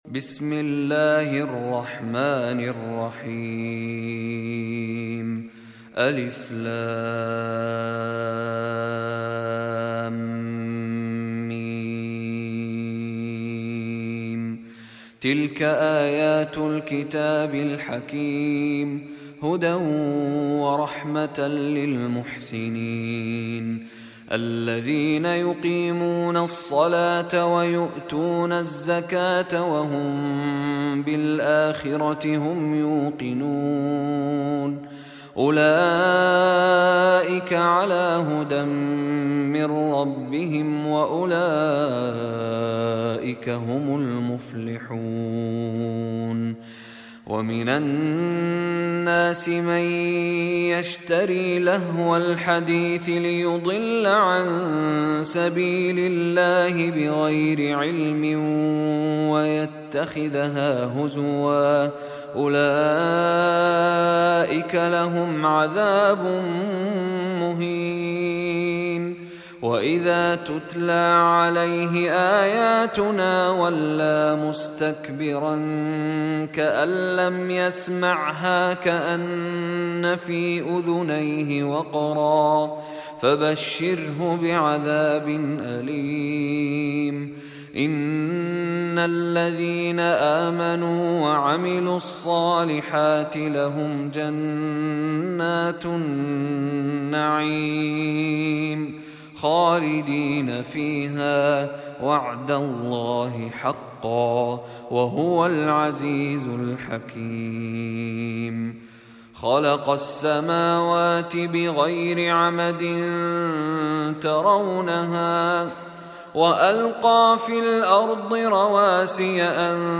الختمة سجلت من صلاة التراويح بولاية كاليفورنيا عام 1430هـ م السورة MP3 تفسير ترجمة م ا...